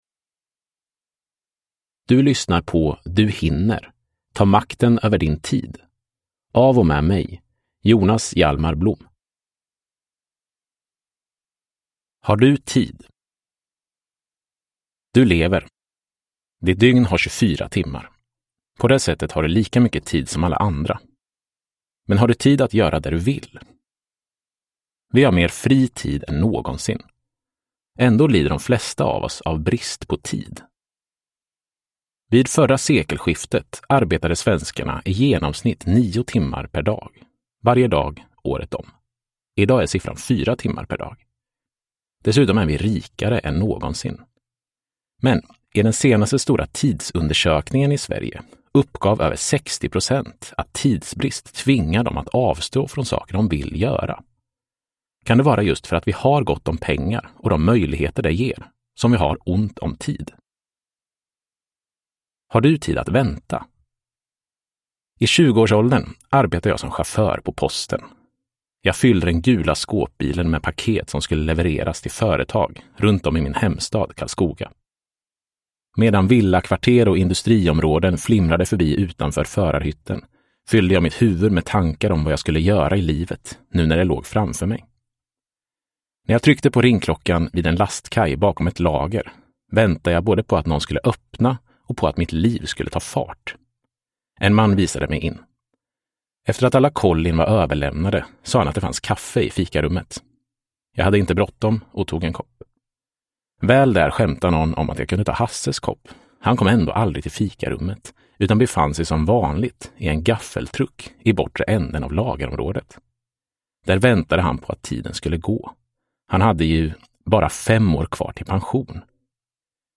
Du hinner! : Ta makten över din tid – Ljudbok